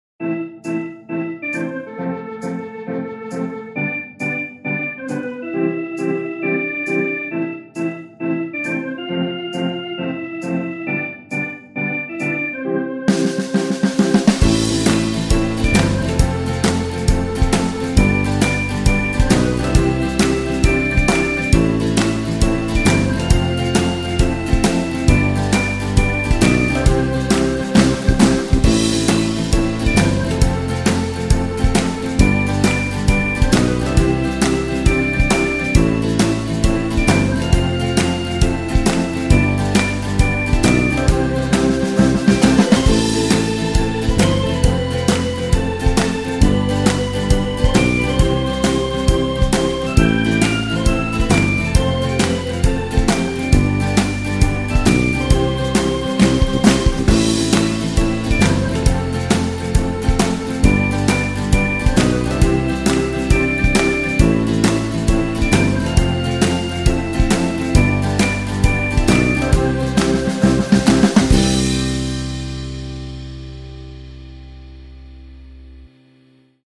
我们在我们的 Sand Creek 工作室里录制了它的最后一息，用两个麦克风位置：近距离和房间。Sandy Creek Organ 包含了六种不同的延音类型（其中两种带有旋转的 Leslie 扬声器），每种都有循环和释放，以及各种音效。